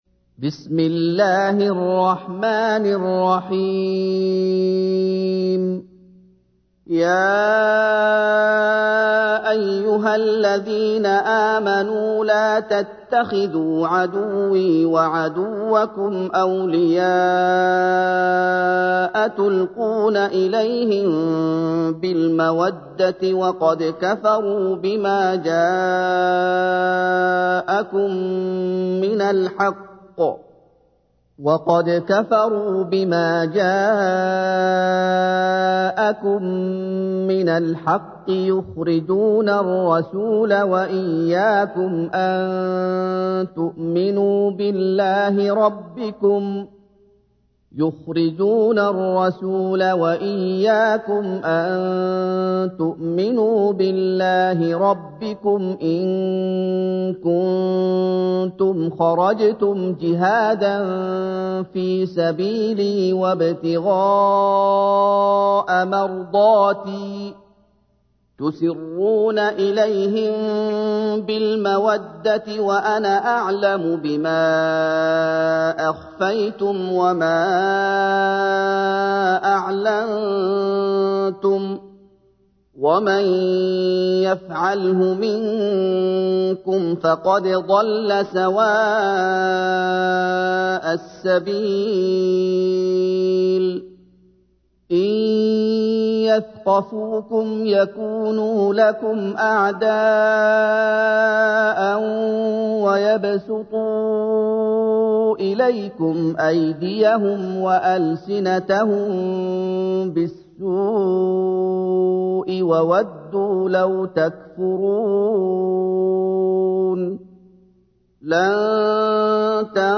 تحميل : 60. سورة الممتحنة / القارئ محمد أيوب / القرآن الكريم / موقع يا حسين